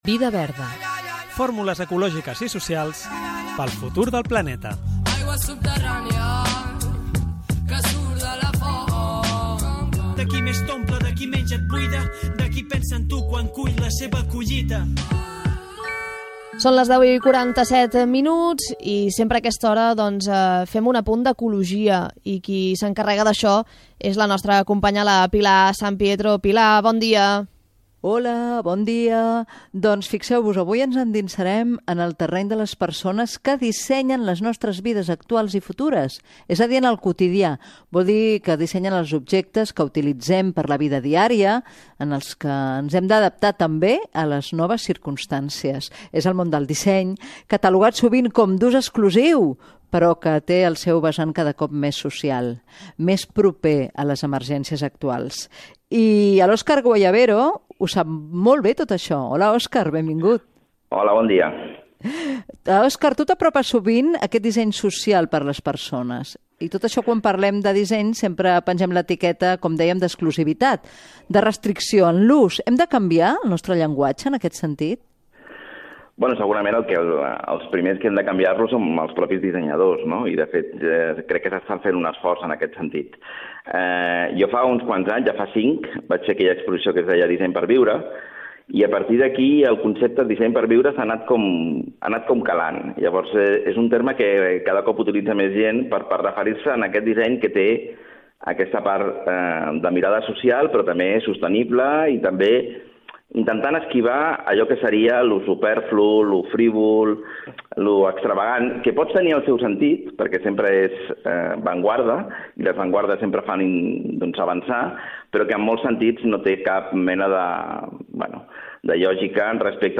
Careta del programa
Gènere radiofònic Divulgació